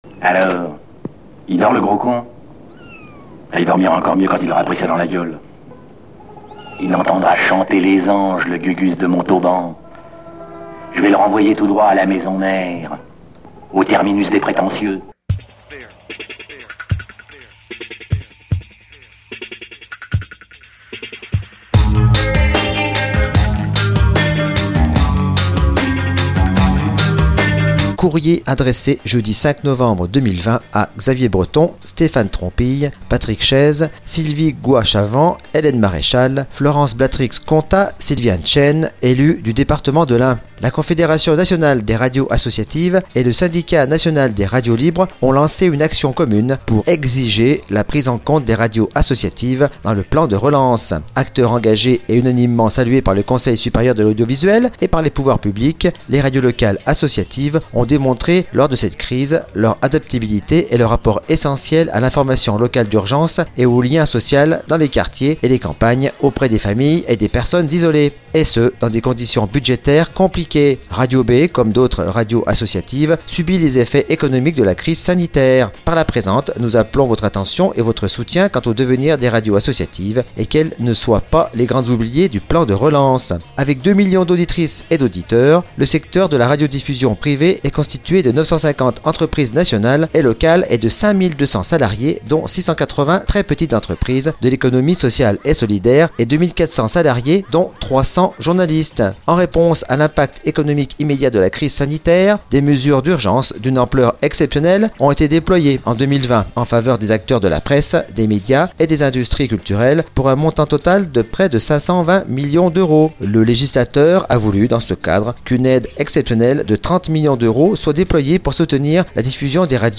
Des chroniques, des coups de coeur, des coups de gueule : l'esprit demeure.